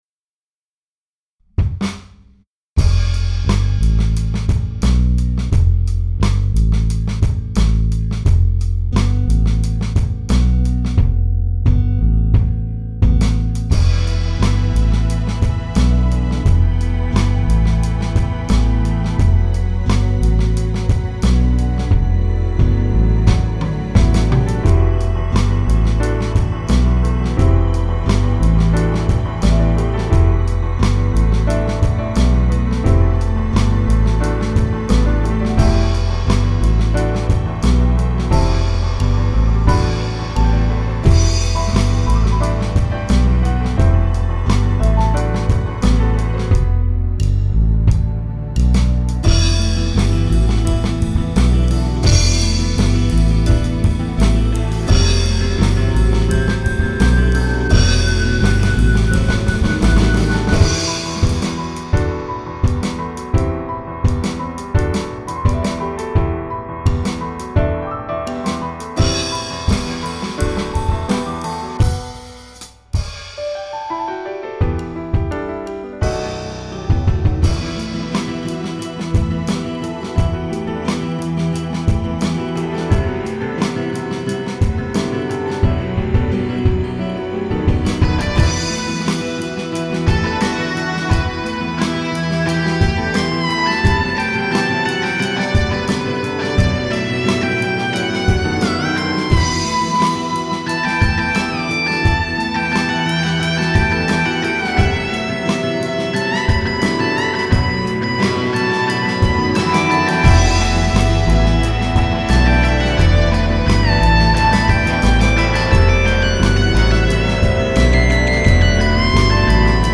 These are links to some sample pieces that I have composed using soundfonts and samples.
Ascending the Skies - drums